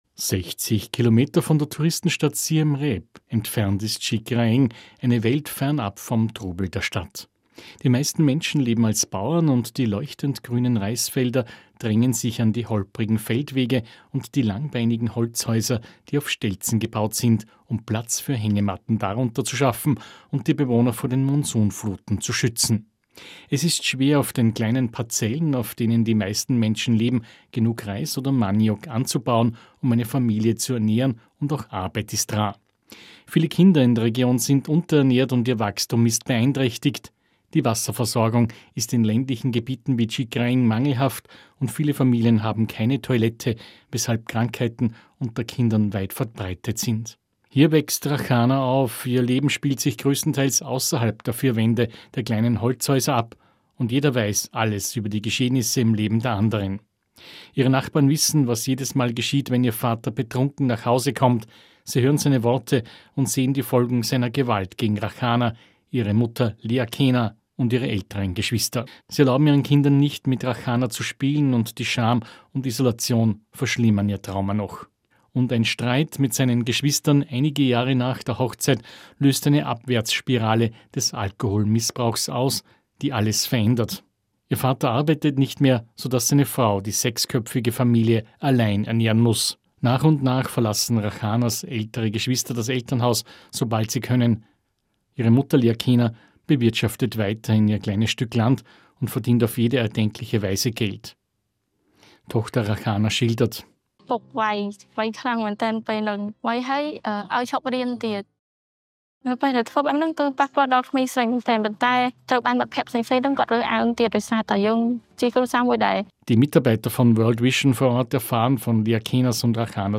Radiobeiträge zu unserer „100 Mädchen“ Kampagne